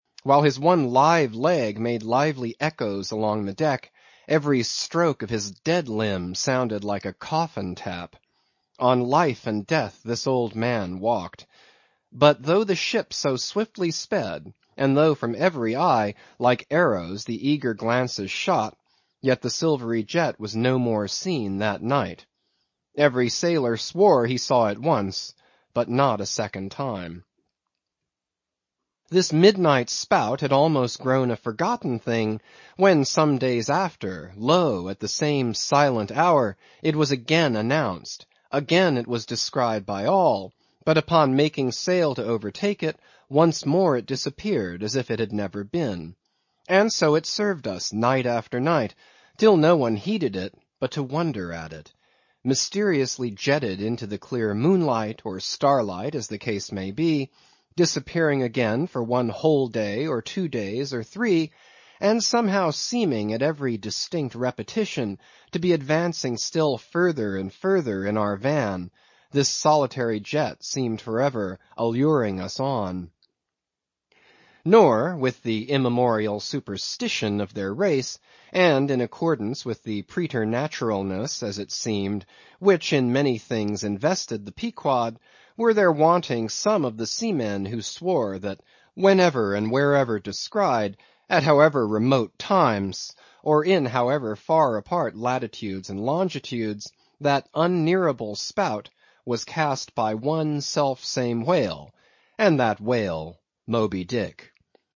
英语听书《白鲸记》第511期 听力文件下载—在线英语听力室